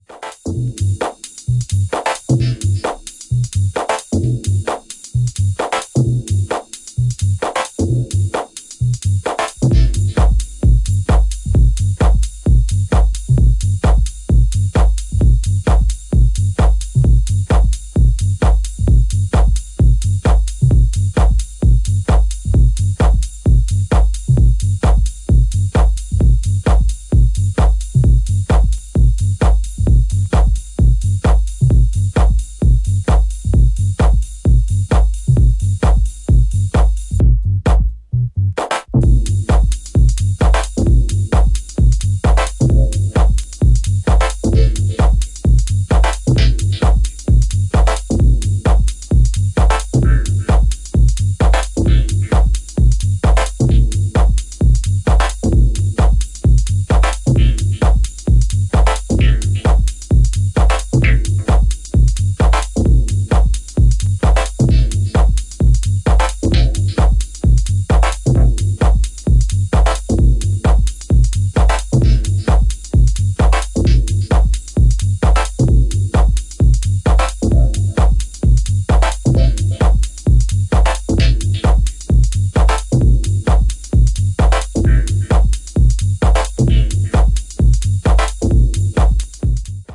Bittersweet atmospheric & raw, pure Techno